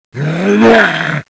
Index of /svencoop/sound/paranoia/zombie
zo_attack1.wav